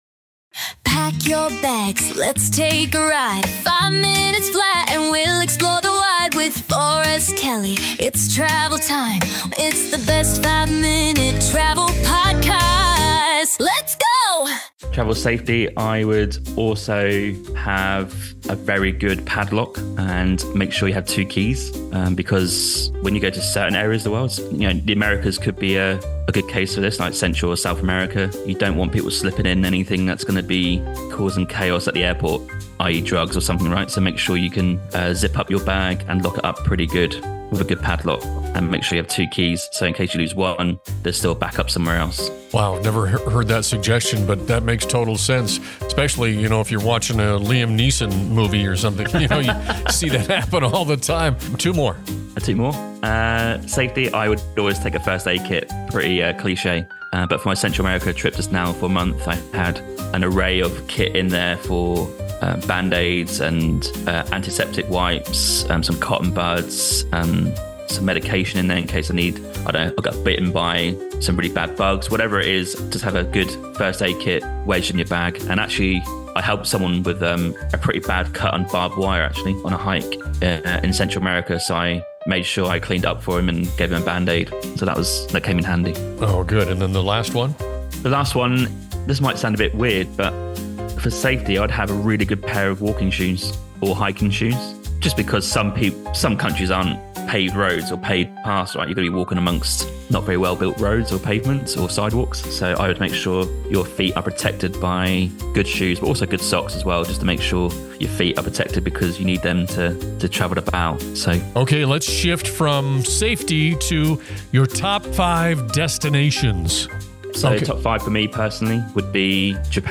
Join them for a lively discussion filled with travel insights, humor, and inspiration for your next adventure!